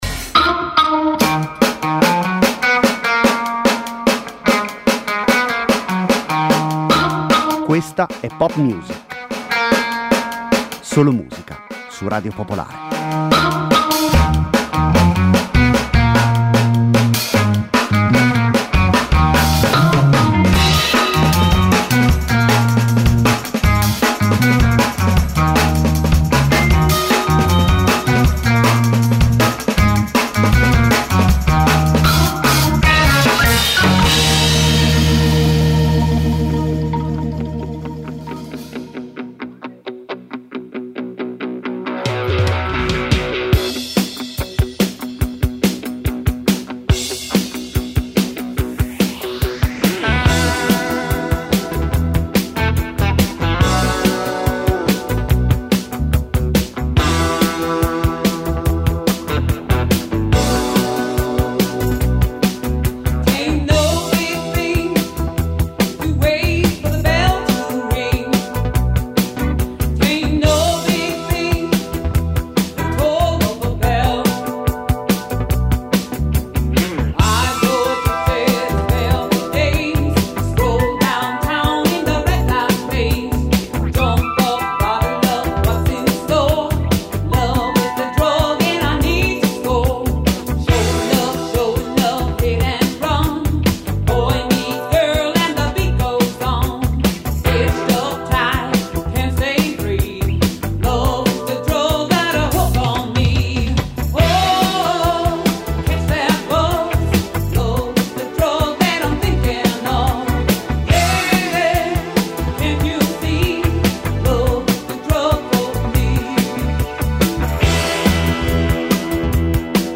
Senza conduttori, senza didascalie: solo e soltanto musica.